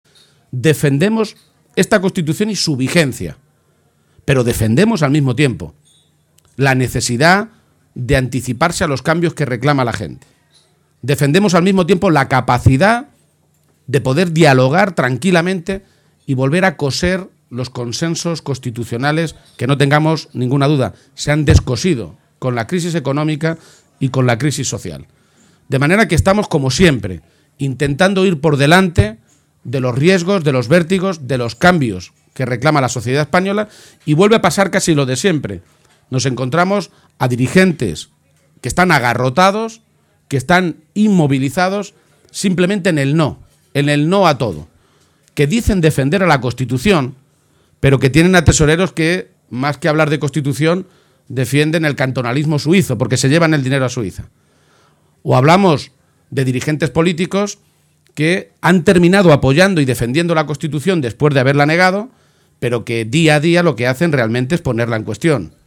Audio Page acto JSE en Albacete-1